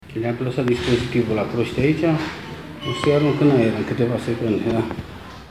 Ambianta-nebun-ii-arunc-in-aer.mp3